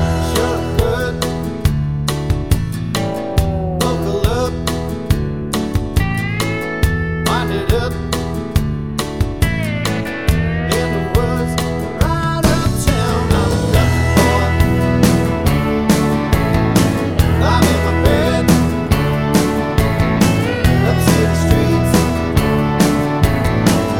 no Backing Vocals Country (Male) 3:59 Buy £1.50